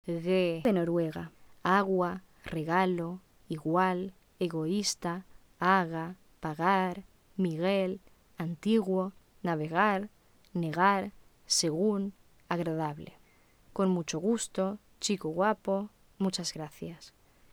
El aire espirado sale así y produce una suave fricción.
[ɣ] de Noruega (g aproximante)